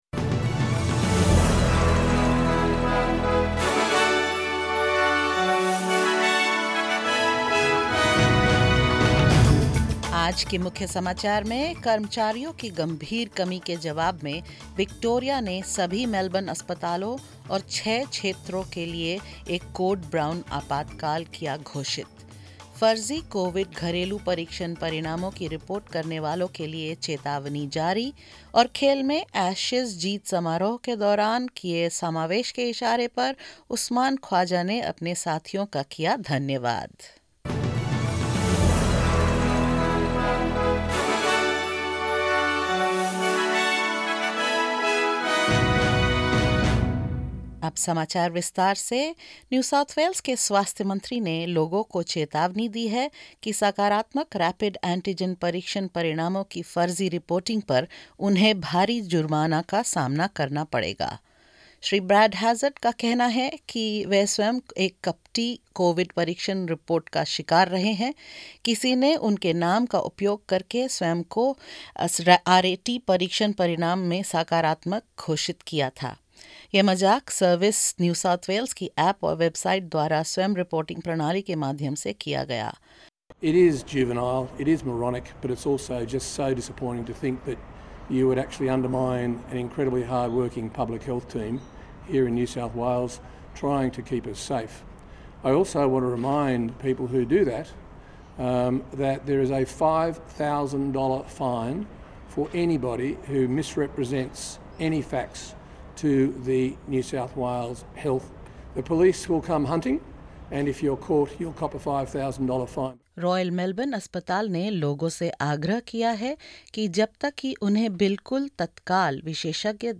In this latest SBS Hindi bulletin: Victoria declares a code brown for hospitals in response to severe staff shortages; New South Wales imposes penalties for those reporting false COVID-19 home test results; In cricket, Usman Khawaja thanks his teammates for their gesture of inclusion during Ashes victory celebrations and more.